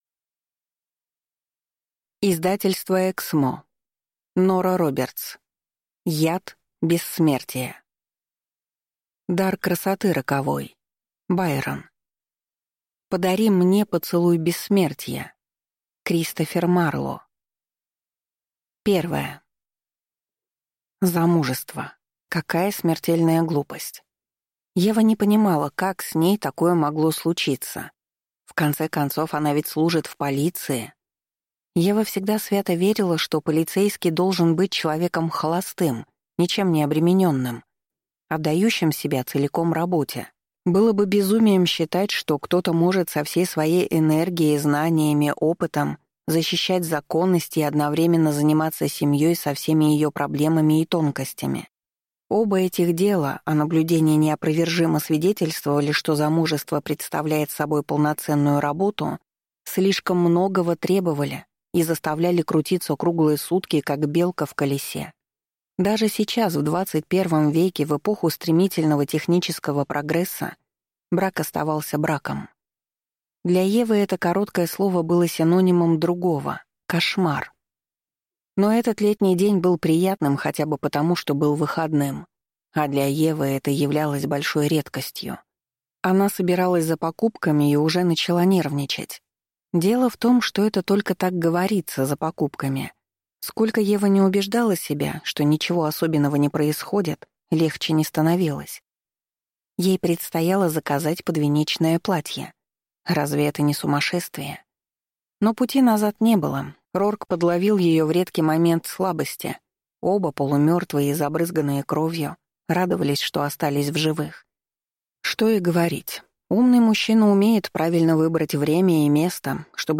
Аудиокнига Яд бессмертия | Библиотека аудиокниг
Прослушать и бесплатно скачать фрагмент аудиокниги